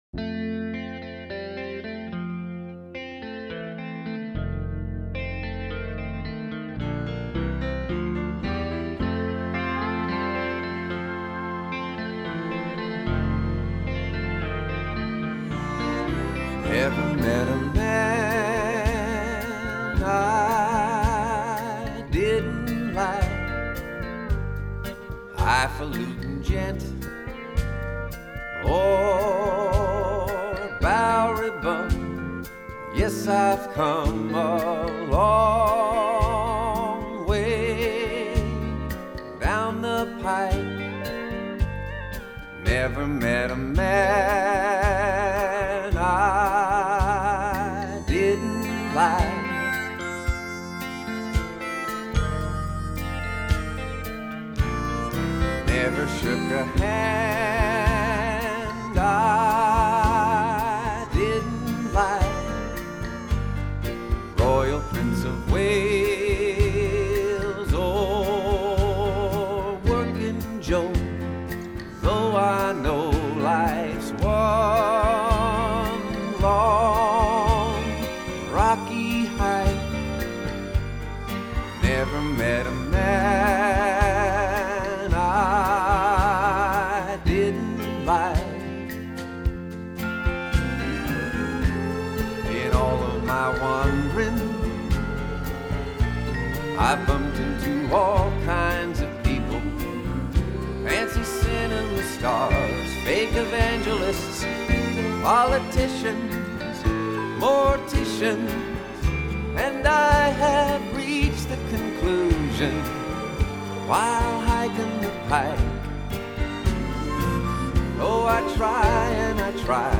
1991   Genre: Musical   Artists